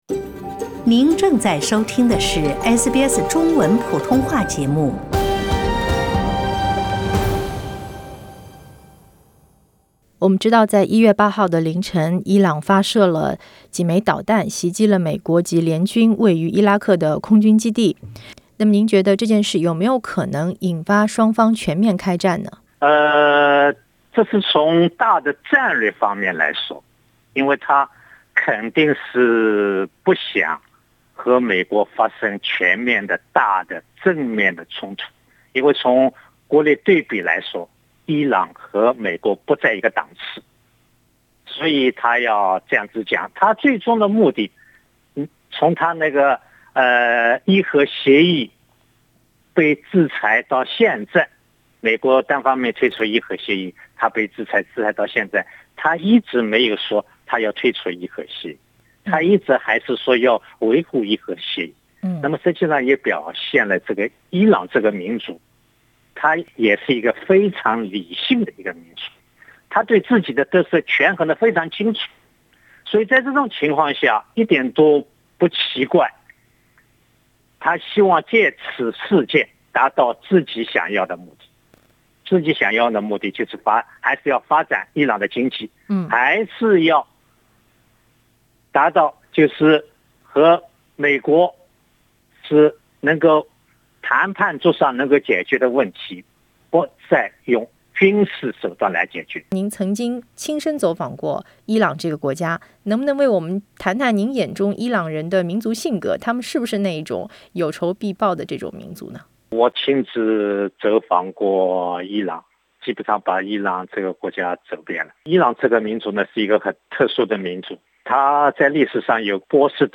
他在接受记者采访时表示，伊朗采取复仇行动有一定的历史文化原因，他们的民族性就是有仇必报，但同时伊朗政府也是非常清醒冷静的，不会轻易引发和美国之间的全面战争。